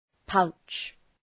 Προφορά
{paʋtʃ}
pouch.mp3